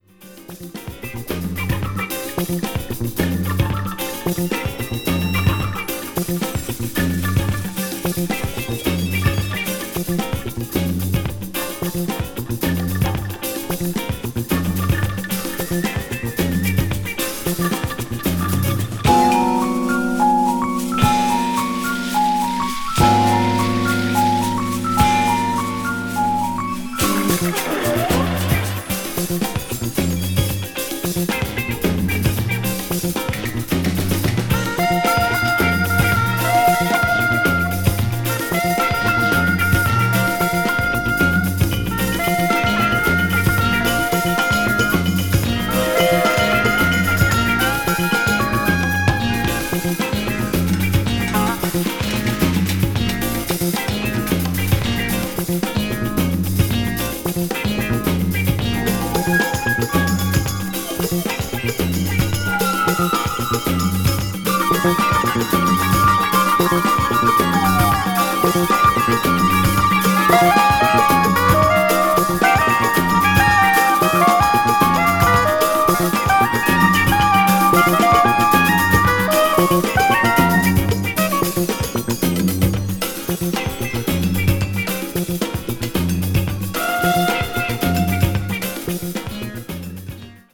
国産エレクトリック・ジャズ・ファンク最高峰。
crossover   electric jazz   fusion   jazz funk   jazz groove